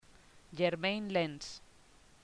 Jeremain LENSYerméin Lens